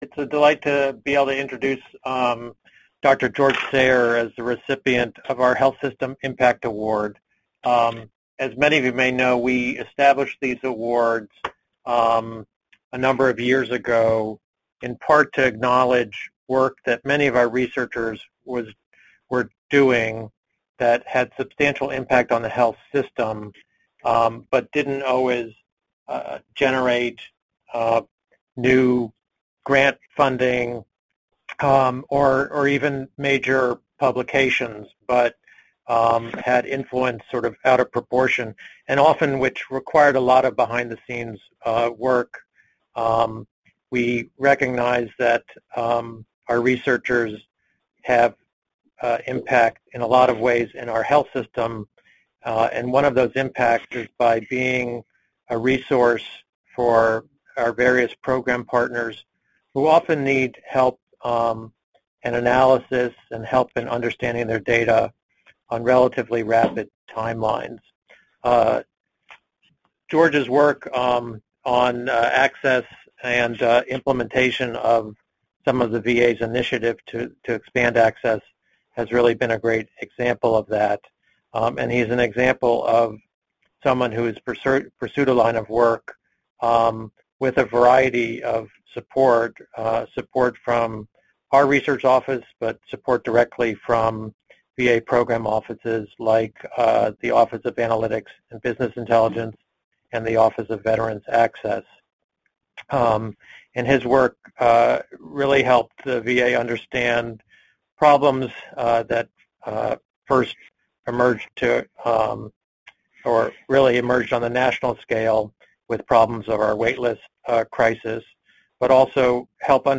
PsyD Seminar date